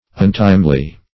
Untimely \Un*time"ly\, a.